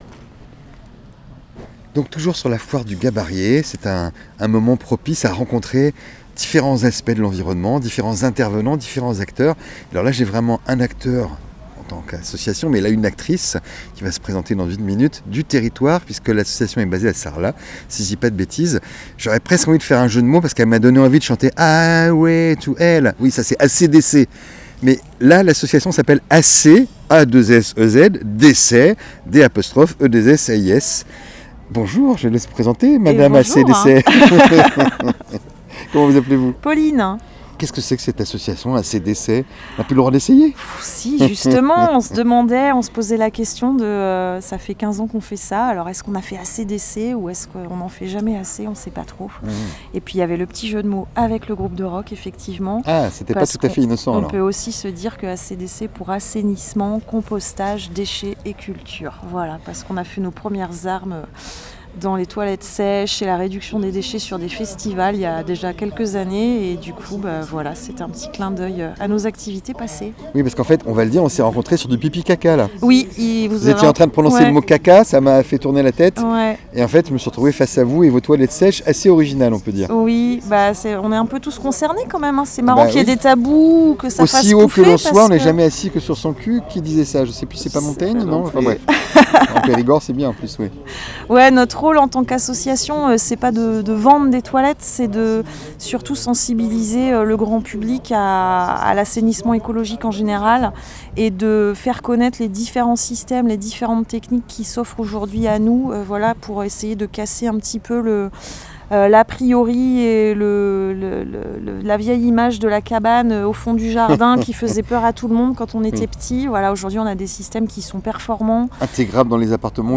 foire-du-gabarrier-itw-asso-Assez-dEssais.wav